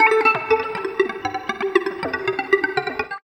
78 GTR 2  -L.wav